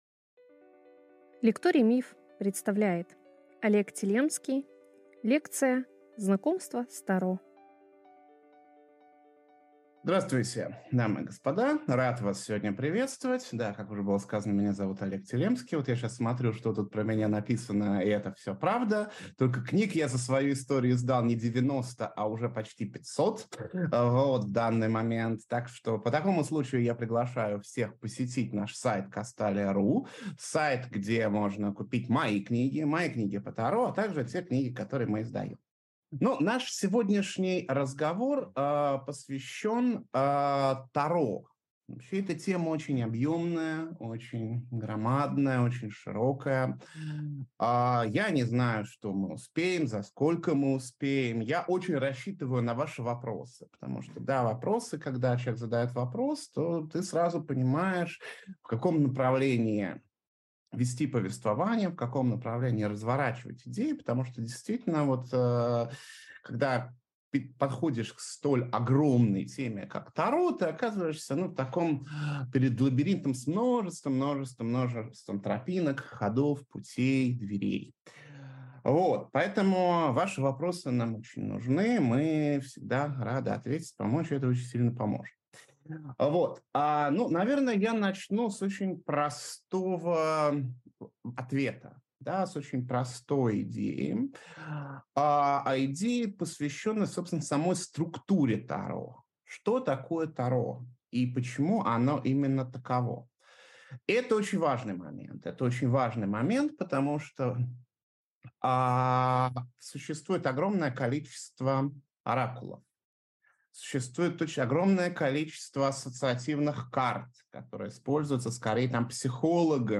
Аудиокнига Лекция 1. «Знакомство с Таро», лекторий «Таро» | Библиотека аудиокниг